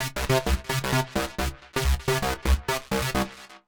Index of /musicradar/uk-garage-samples/130bpm Lines n Loops/Synths